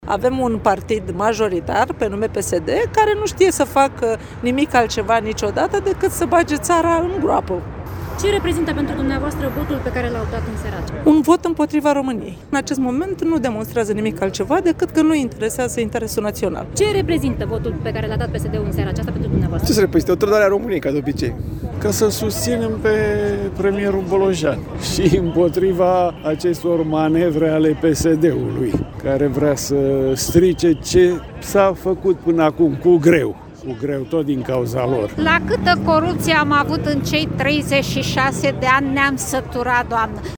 Câteva sute de persoane s-au strâns în Piața Victoriei din Capitală, în sprijinul lui Ilie Bolojan.
„Avem un partid majoritar, anume PSD, care nu știe să facă nimic altceva niciodată decât să bage țara în groapă”, a spus o femeie.
„Reprezintă o trădare a României, ca de obicei. Suntem aici ca să-l susținem pe premierul Ilie Bolojan și împotriva acestor manevre ale PSD care vrea să strice ce s-a făcut până acum cu greu”, a declarat un bărbat.
20apr-21-VIO-VOX-Protest-Piata-Victoriei-.mp3